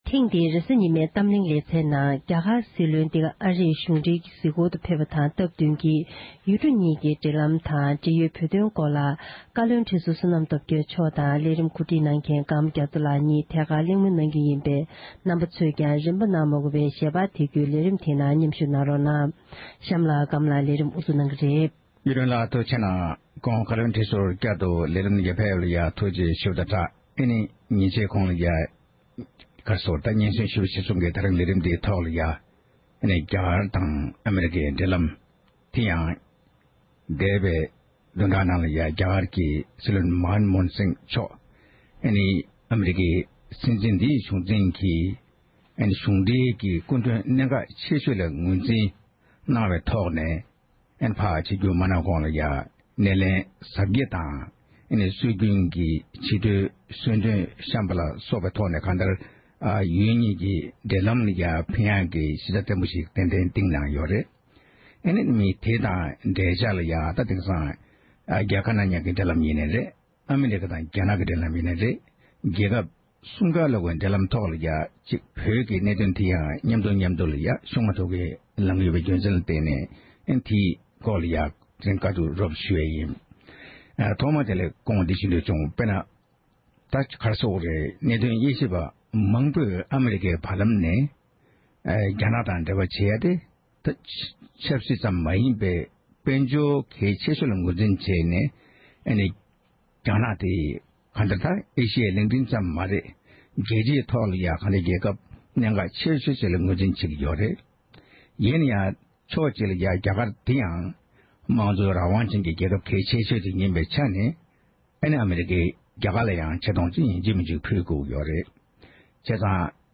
གླེང་མོལ་གནང་བ་ཞིག་གསན་རོགས༎